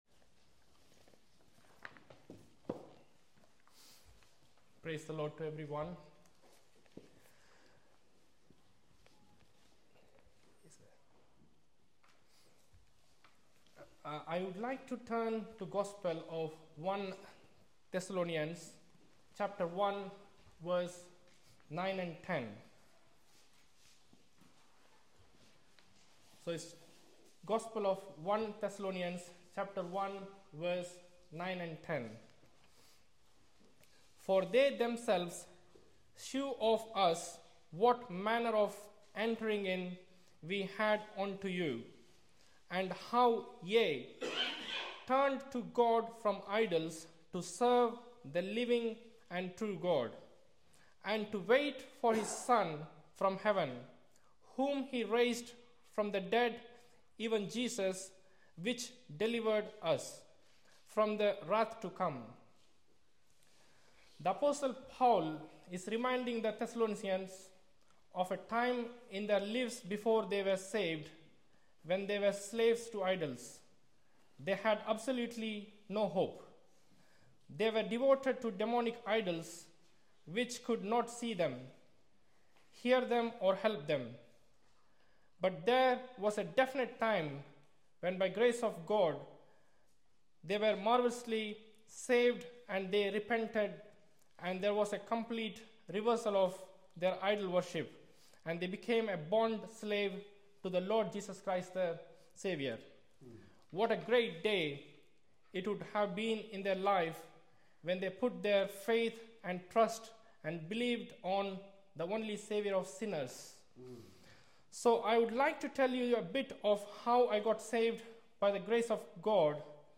Personal Testimonies